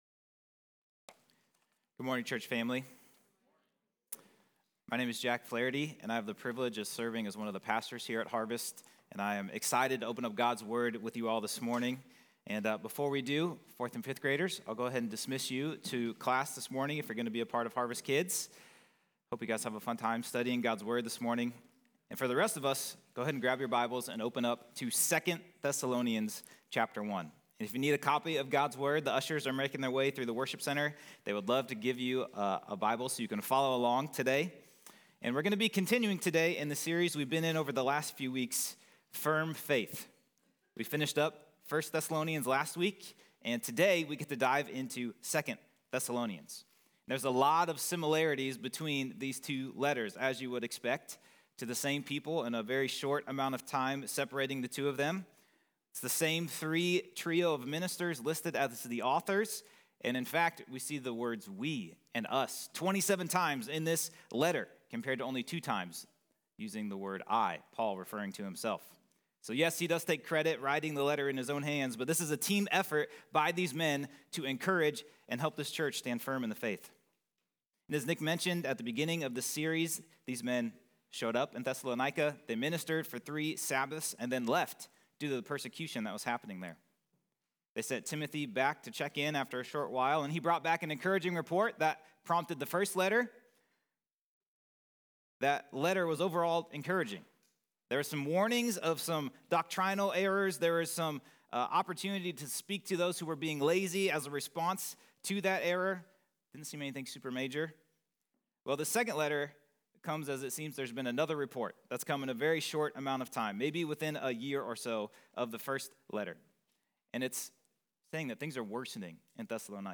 Good morning church family!